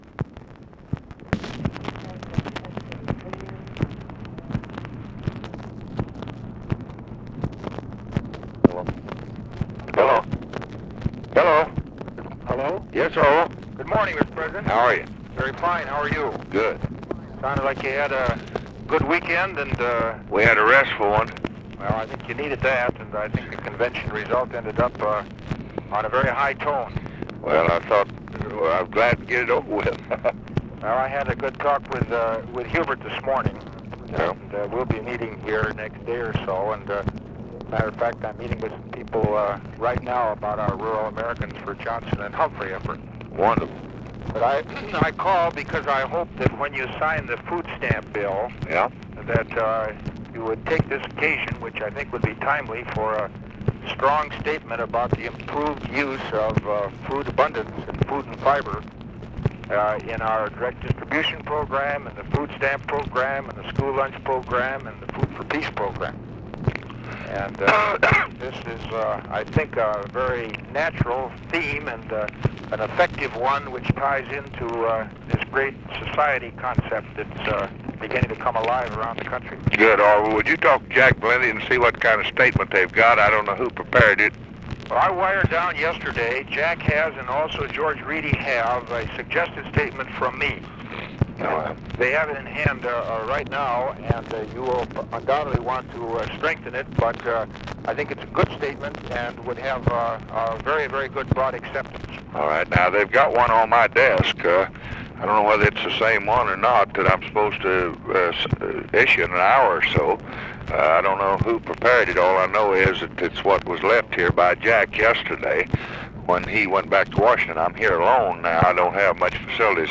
Conversation with ORVILLE FREEMAN, August 31, 1964
Secret White House Tapes